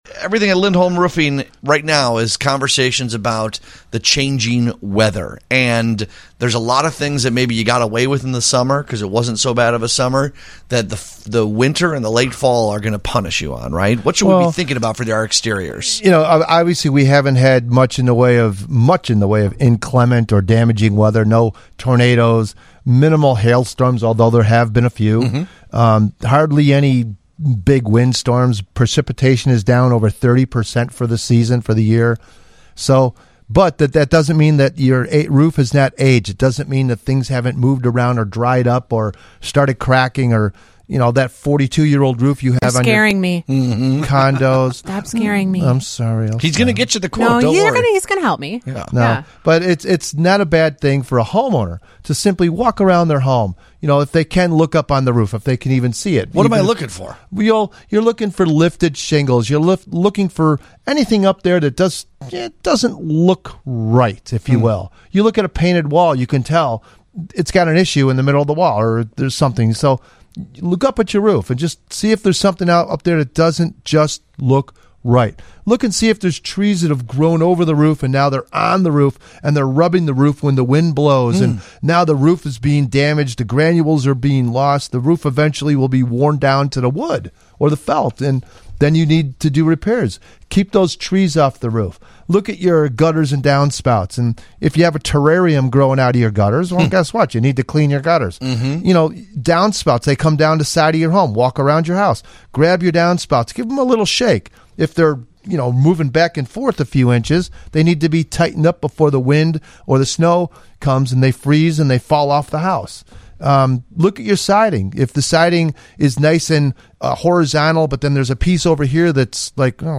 Featured on WGN Radio’s Home Sweet Home Chicago on 09/13/25